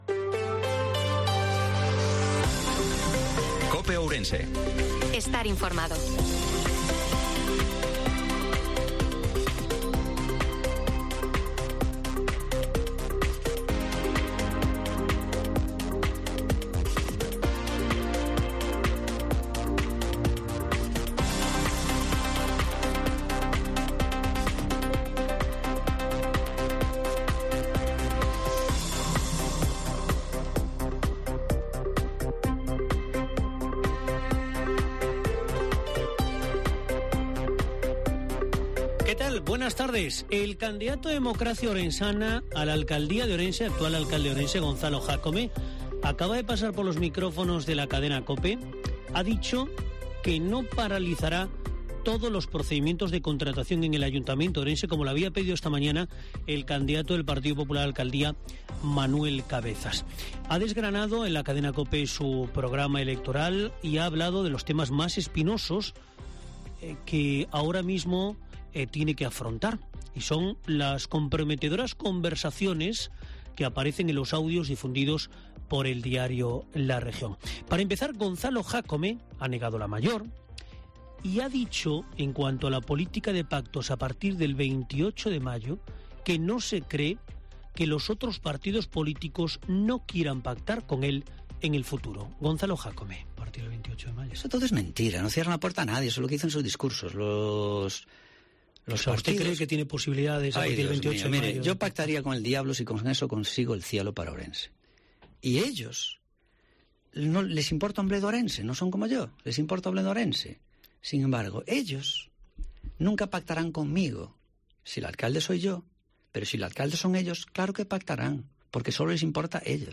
INFORMATIVO MEDIODIA DIA COPE OURENSE-23/05/2023